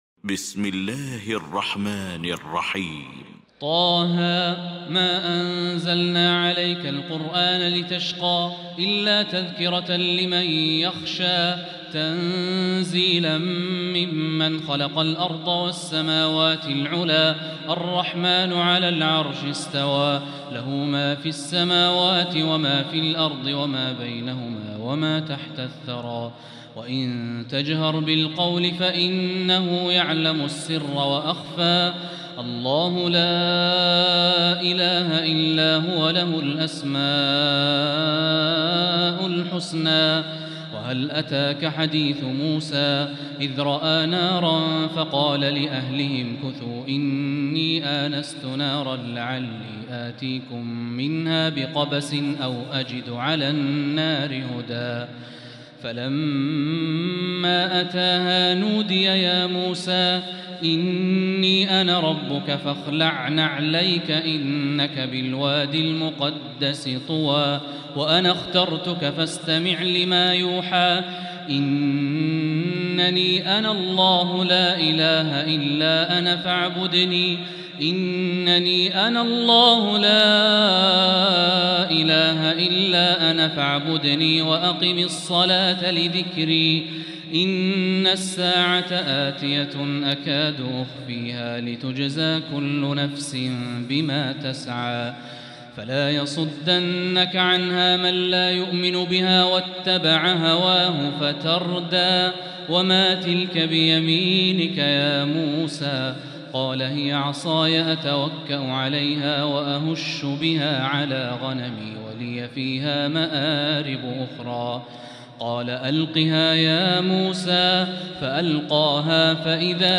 المكان: المسجد الحرام الشيخ: فضيلة الشيخ د. الوليد الشمسان فضيلة الشيخ د. الوليد الشمسان فضيلة الشيخ عبدالله الجهني طه